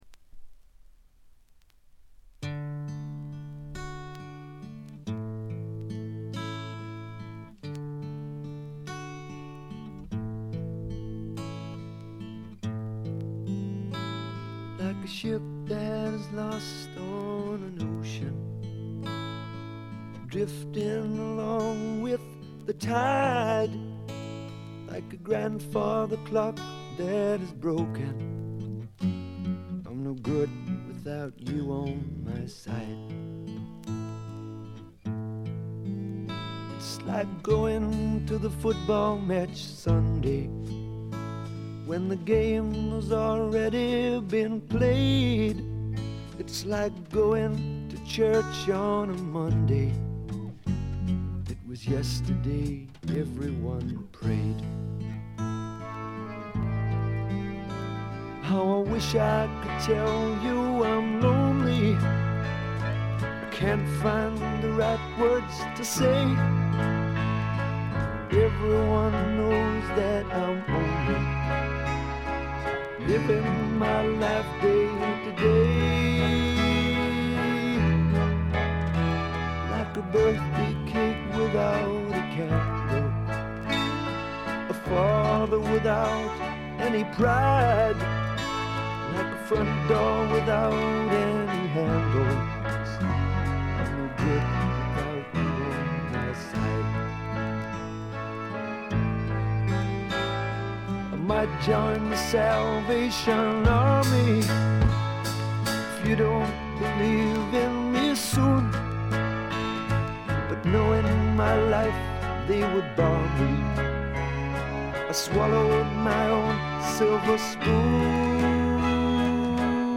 静音部でチリプチ。
ちょいと鼻にかかった味わい深いヴォーカルがまた最高です。
試聴曲は現品からの取り込み音源です。